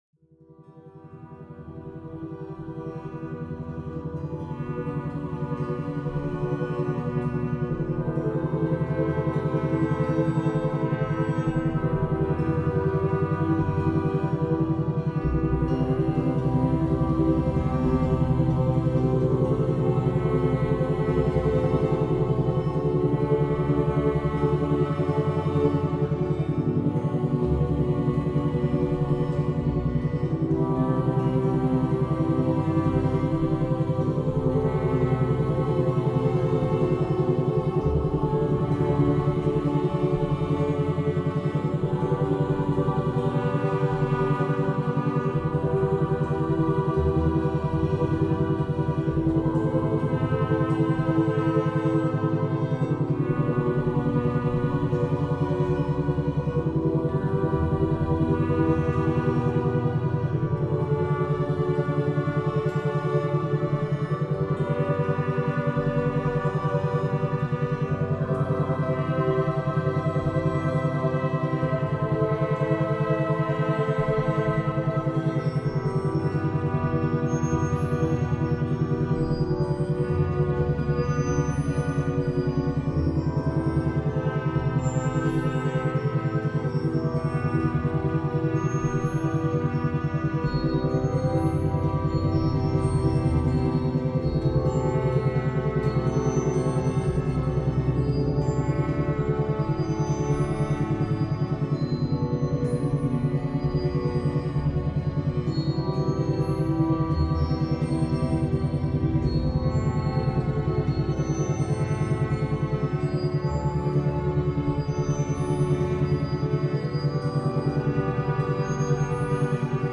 勉強BGM